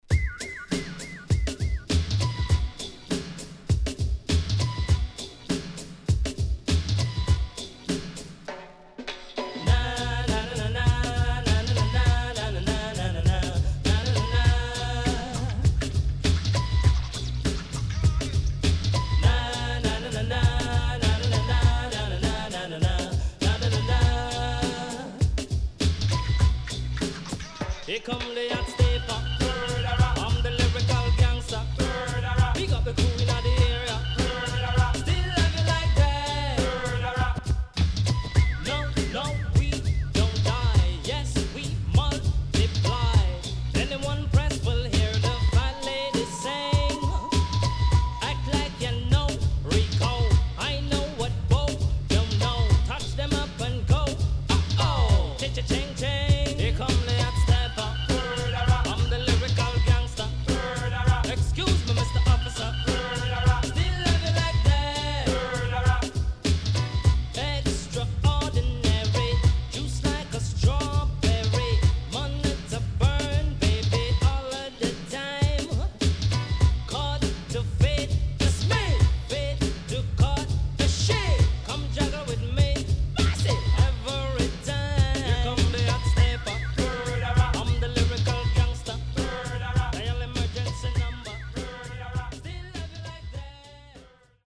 HOME > LP [DANCEHALL]
SIDE B:少しチリノイズ入りますが良好です。